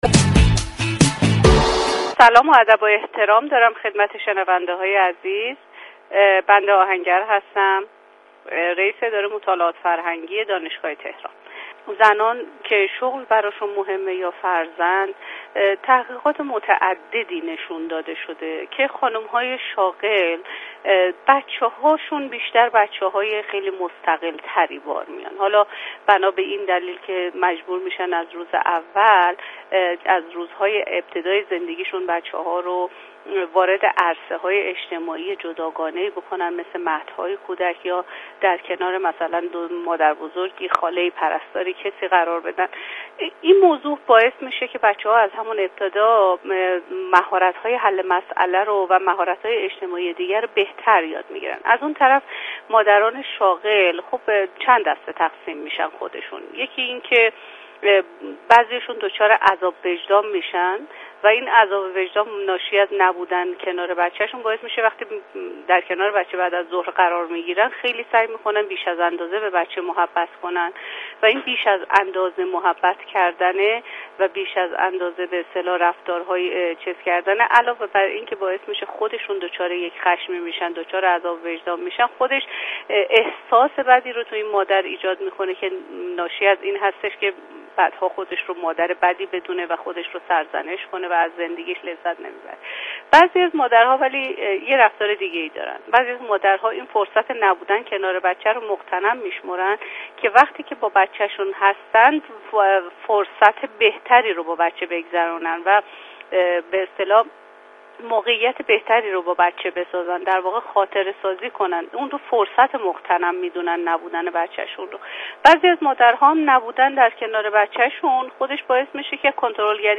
در گفت و گو با برنامه "مهربانو" رادیو تهران